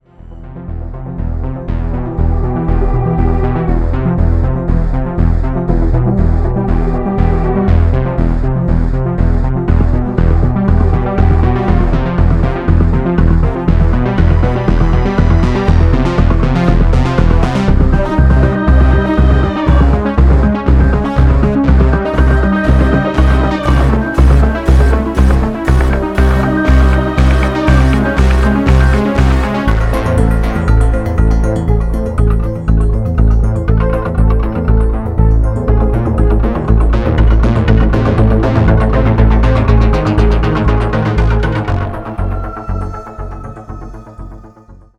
blending electronic sounds and acoustic strings